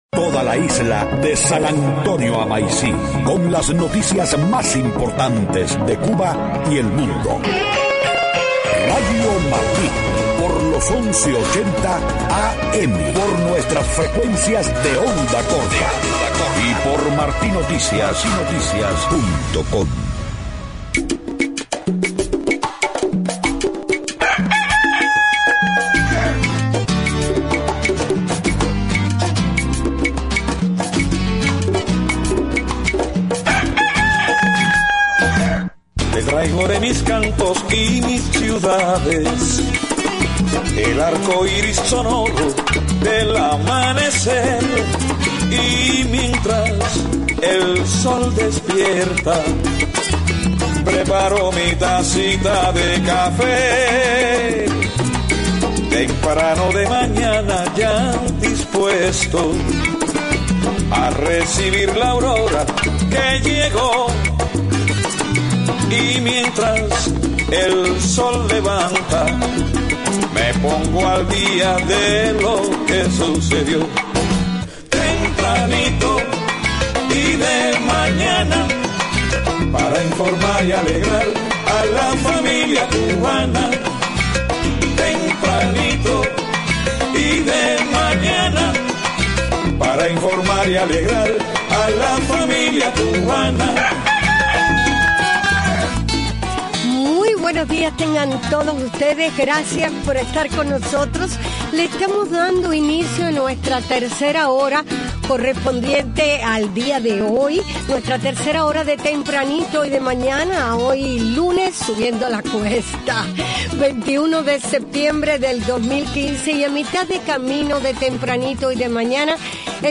7:00 a.m Noticias: Grupos opositores en Cuba reportan decenas de arrestos durante la primera jornada del papa Francisco en la isla. Arzobispo de Miami, Thomas Wenski, compartió con Radio Martí sus impresiones de la primera misa oficiada por el papa Francisco en Cuba.